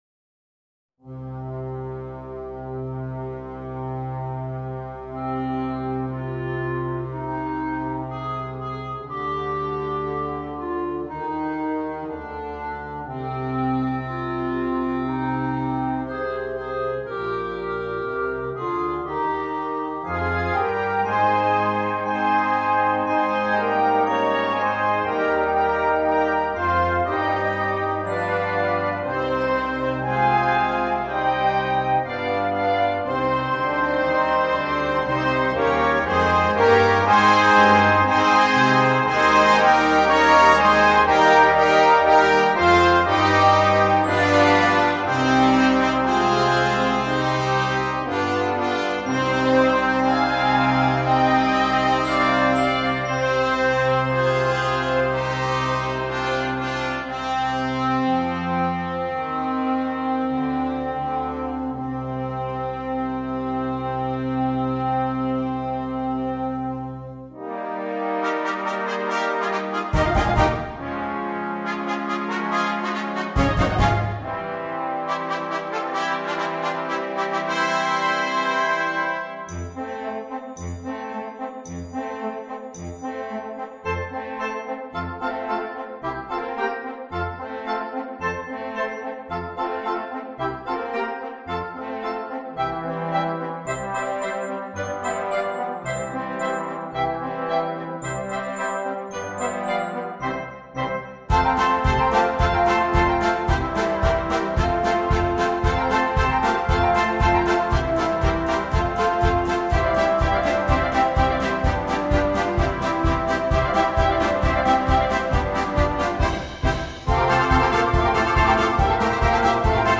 MUSICA PER BANDA